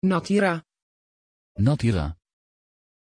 Pronunciation of Natyra
pronunciation-natyra-nl.mp3